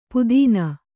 ♪ pudīna